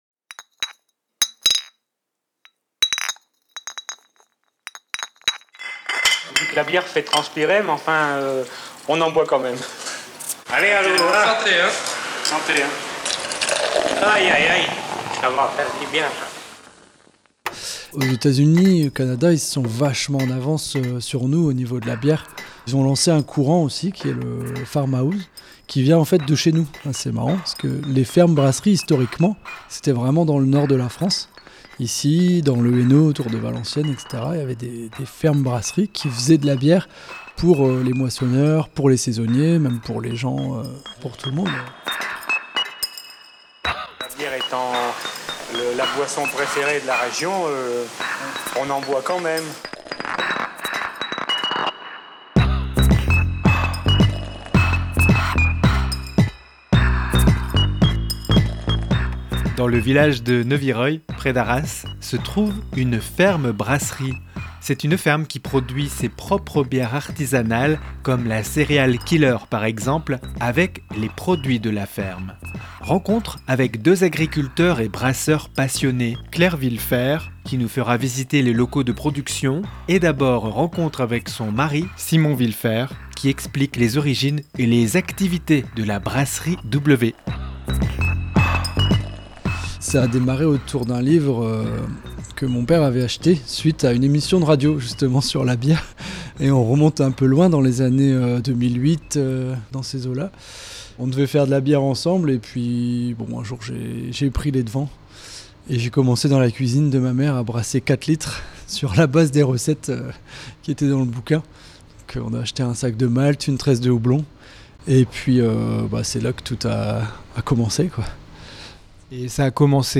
REPORTAGE-2507-Visite-de-la-ferme-brasserie-W-a-Neuvireuil.mp3